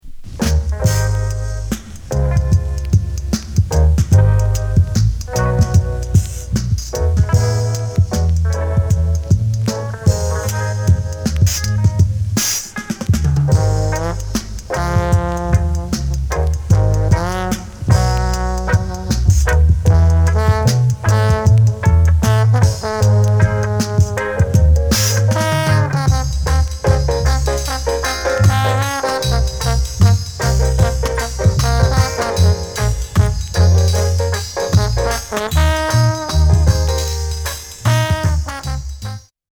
ワイルド・トロンボーン・インスト・レゲー大傑作！！
ブラック・アーク録音